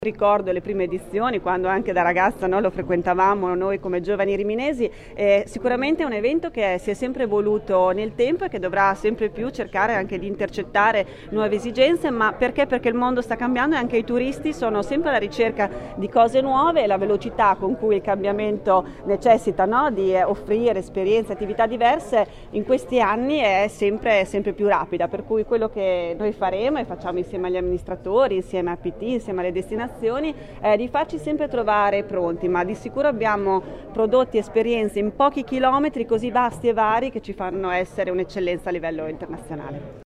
Ascolta l’intervista all’assessora regionale al turismo, commercio e sport Roberta Frisoni: